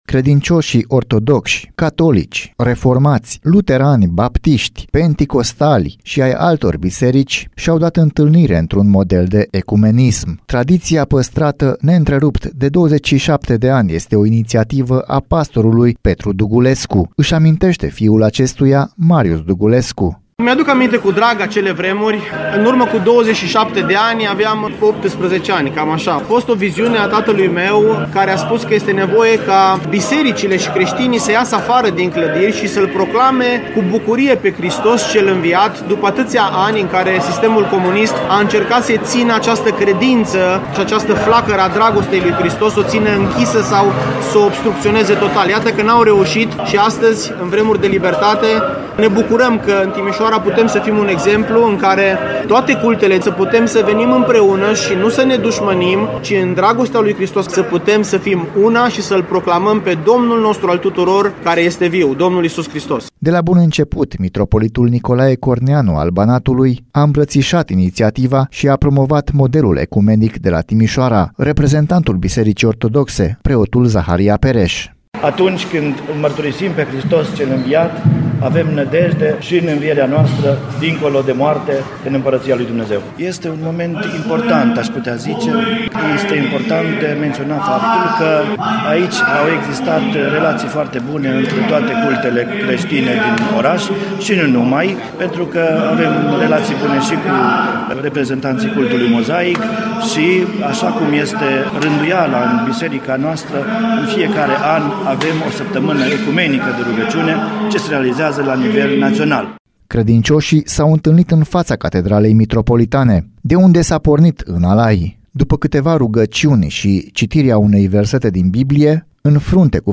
După câteva rugăciuni şi citiri din Biblie, în fruntea cu fanfarerele bisericilor creştine, participanţii au plecat în marş spre Primărie.